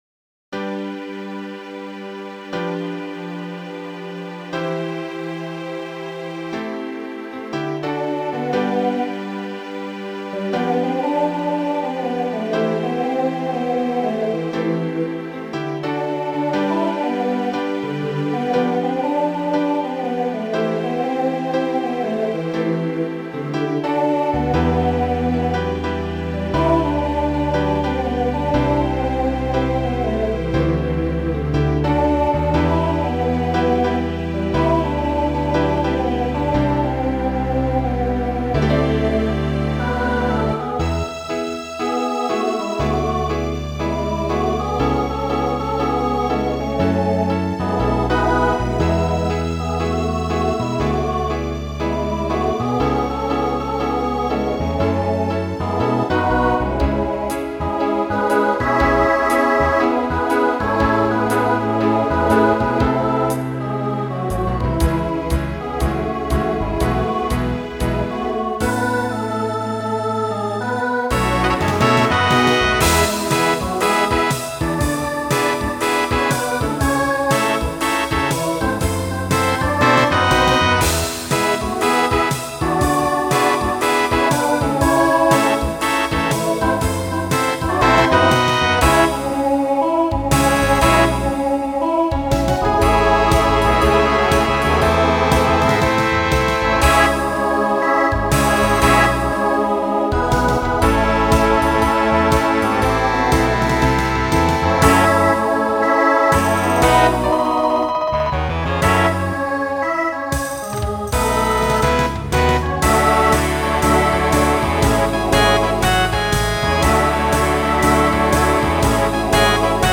Voicing SATB Instrumental combo Genre Broadway/Film
Mid-tempo , Novelty